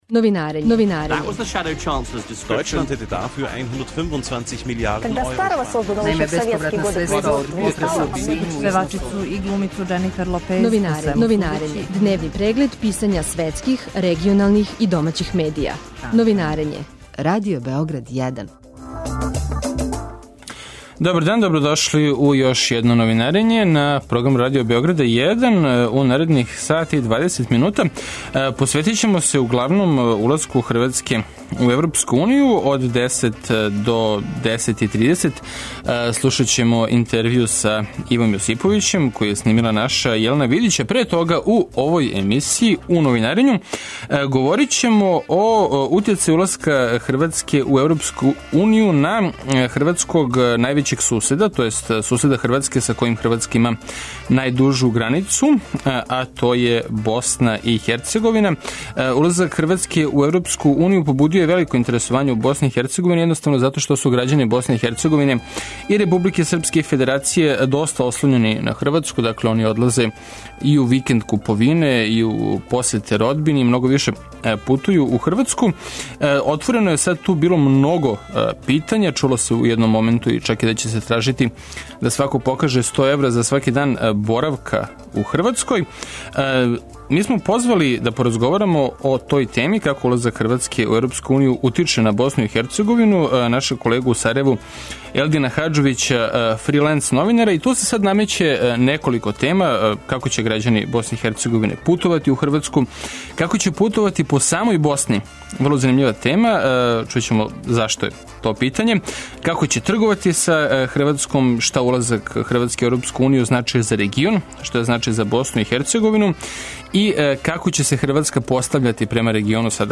Све су ово поводи за разговор са нашим колегом у Сарајеву, freelance новинаром